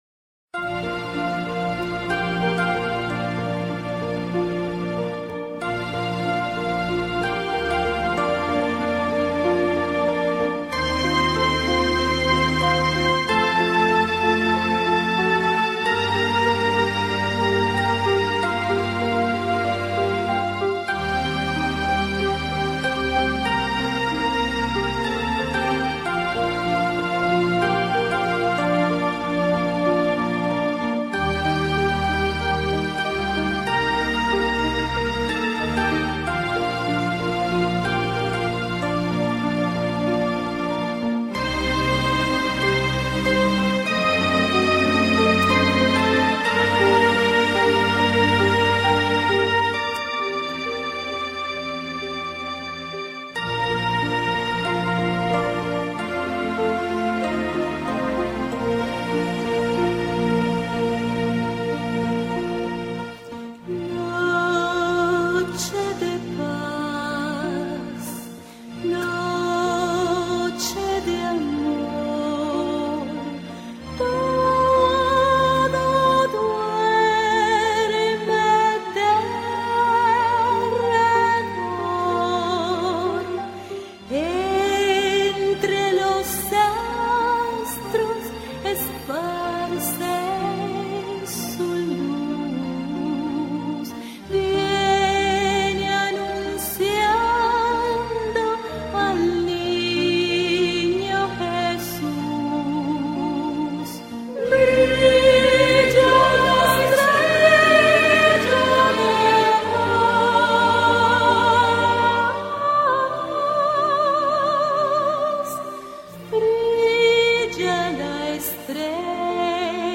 on 2008-12-25 - Canciones navideñas